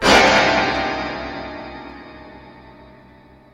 A rather nice moment at the end of a (not very good) take